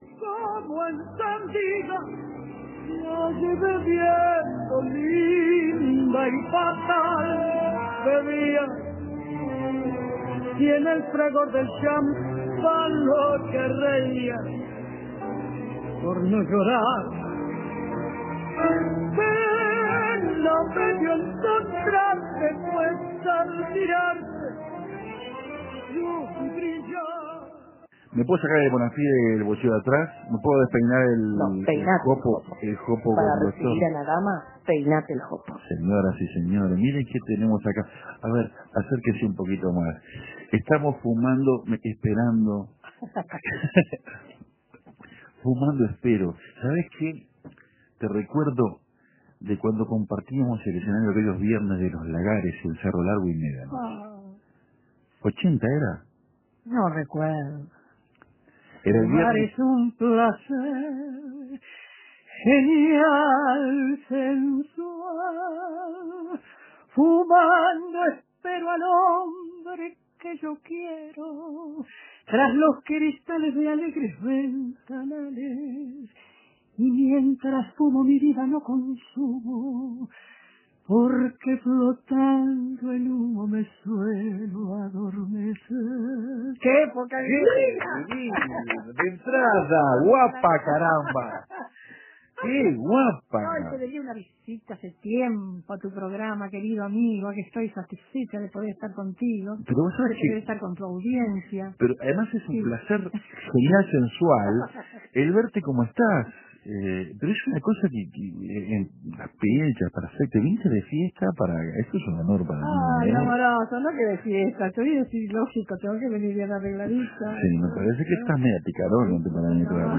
Olga Delgrossi estuvo en Café Torrado y contó historias al ritmo del 2 por 4. También, las cantó.